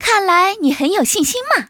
文件 文件历史 文件用途 全域文件用途 Choboong_tk_04.ogg （Ogg Vorbis声音文件，长度0.0秒，0 bps，文件大小：24 KB） 源地址:游戏语音 文件历史 点击某个日期/时间查看对应时刻的文件。